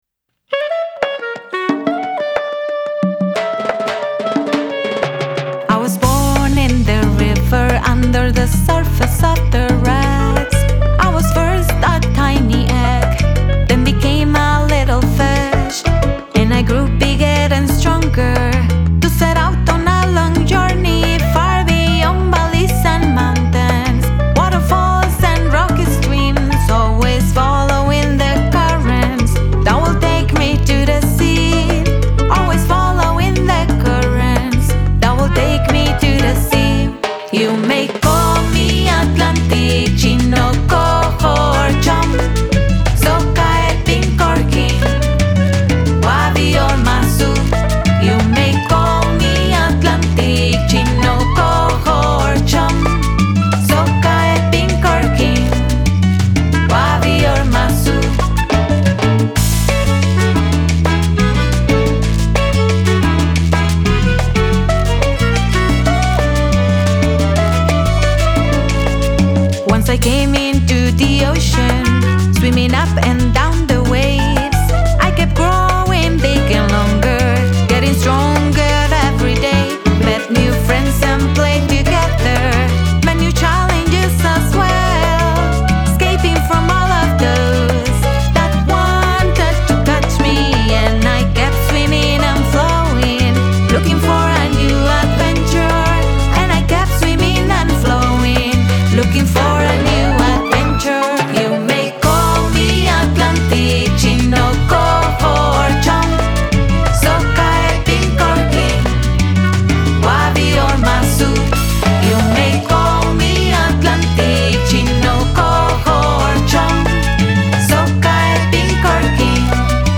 Lead & Backing Vocals
Backing Vocals
Piano
Clarinet
Violins
Percussion & Bass
Vocals recorded at